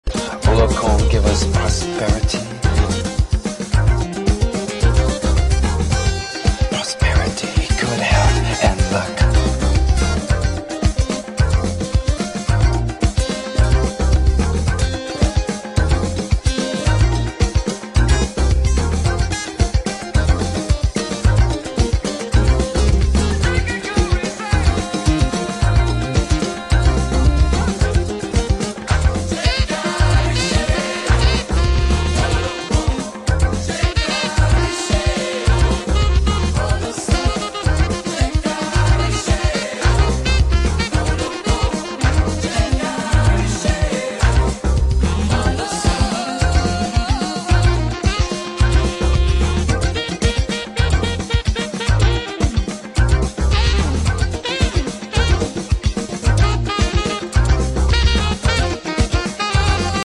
This is the peak of electronic Afro-beat!